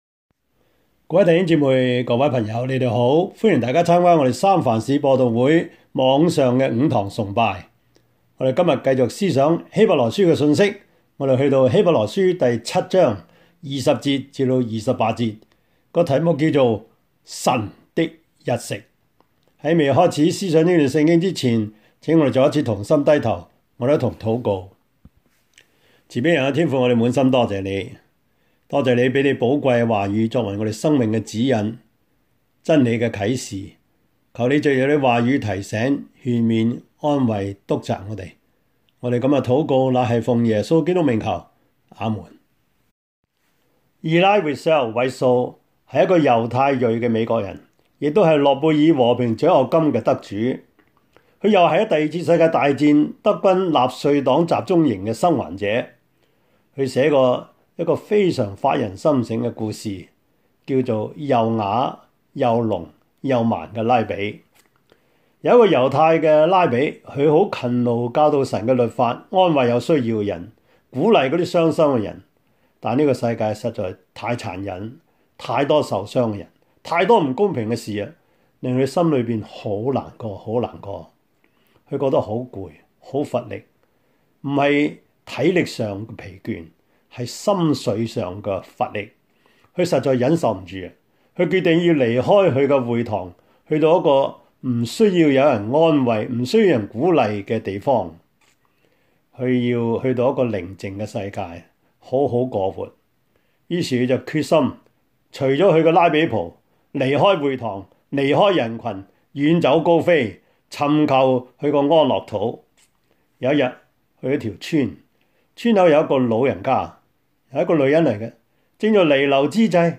Service Type: 主日崇拜
Topics: 主日證道 « 建立聖徒 第八課: 評馬禮遜傳教策略與影響 »